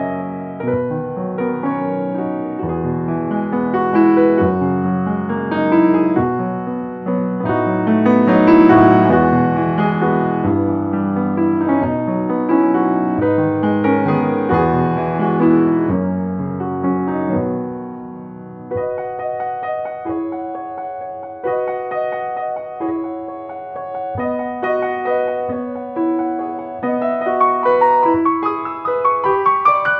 14 original, easy listening piano solos.